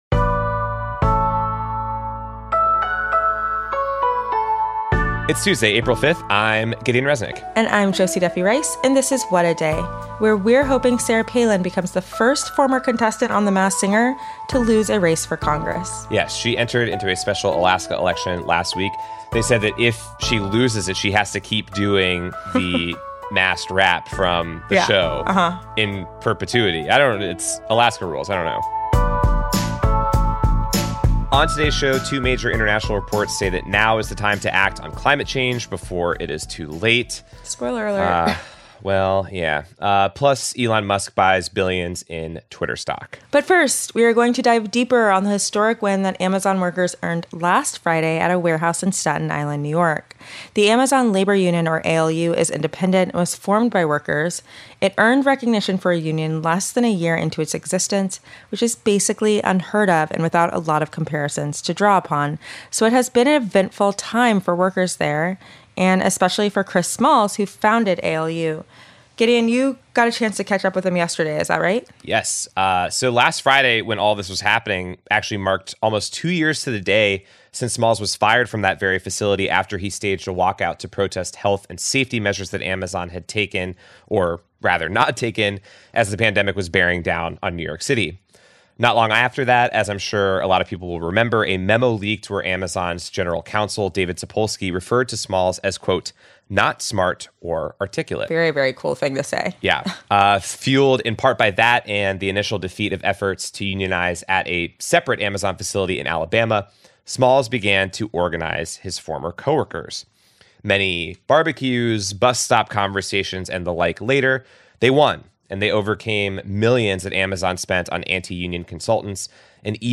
Chris Smalls, founder of the Amazon Labor Union, joins us to discuss how it felt to win and what comes next.